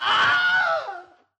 willhelm.mp3